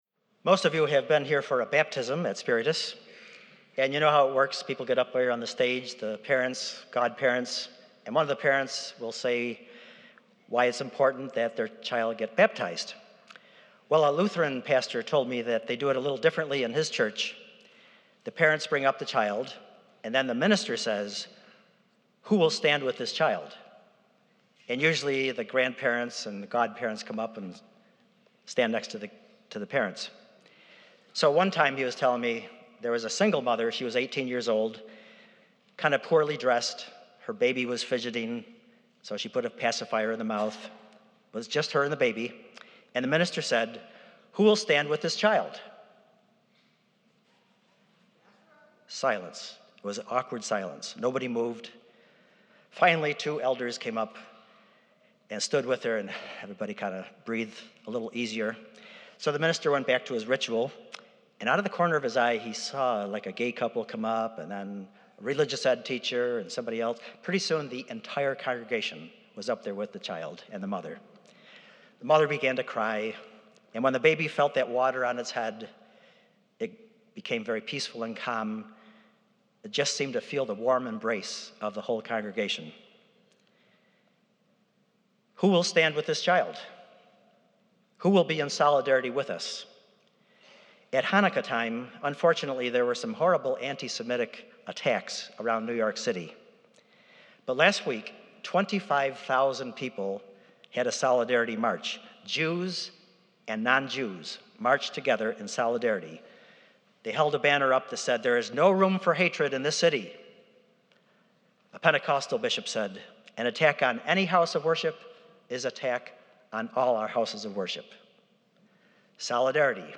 Have you ever worried about identity theft? It is a spiritual problem too. Full Mass.